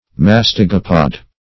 \mas"ti*go*pod\